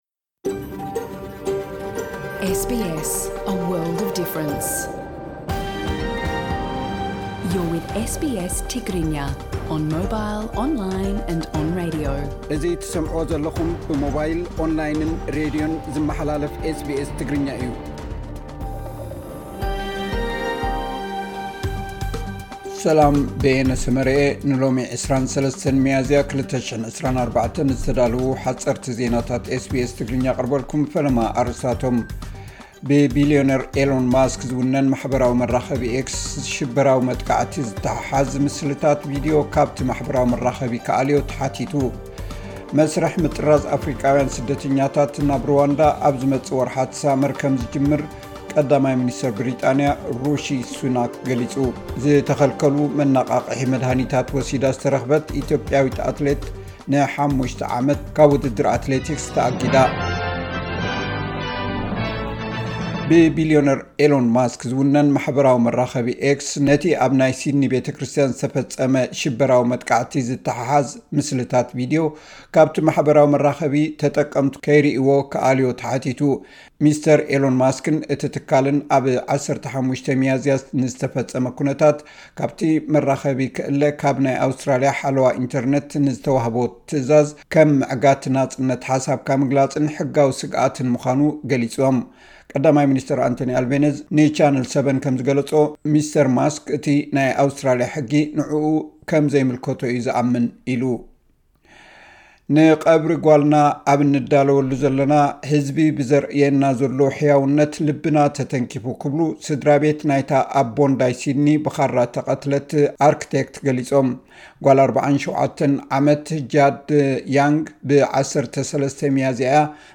ሓጸርቲ ዜናታት ኤስ ቢ ኤስ ትግርኛ (23 ሚያዝያ 2024)